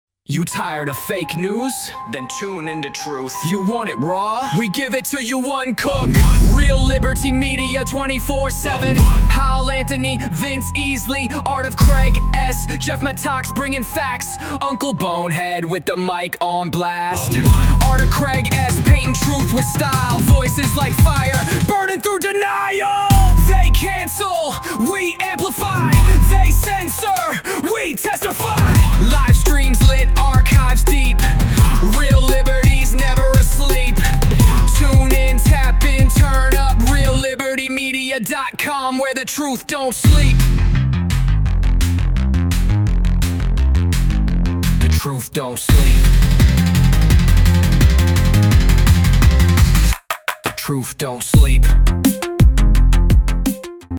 Station ID - Bumper - 53 Secs.mp3